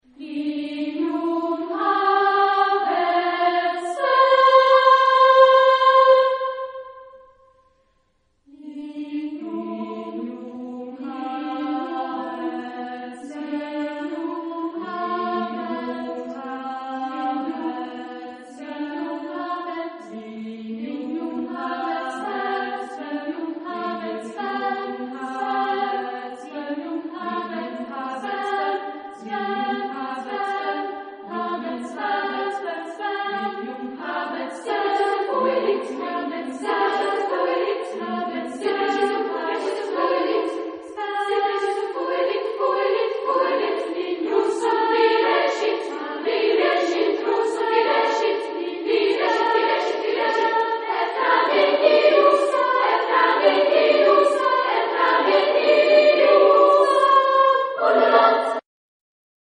Genre-Style-Form: Sacred ; Choral prelude
Type of Choir: SSAA  (4 women voices )